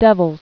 (dĕvĭlz)